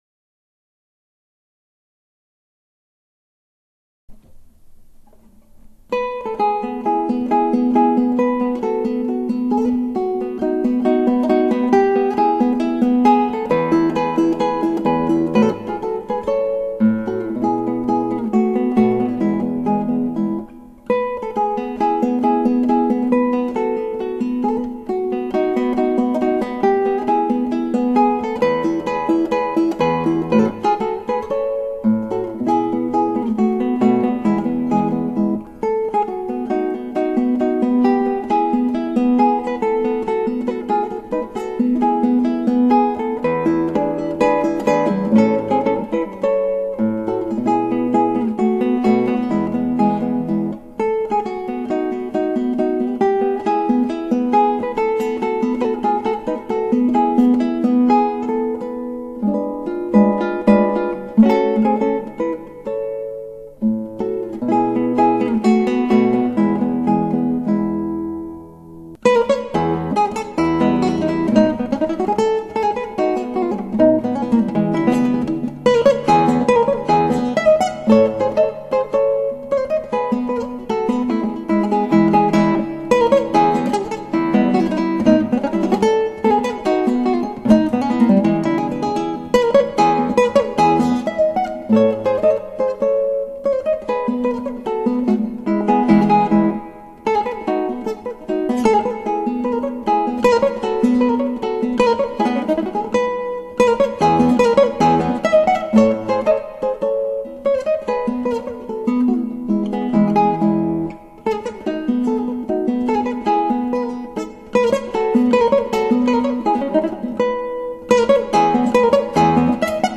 クラシックギター　ストリーミング　コンサート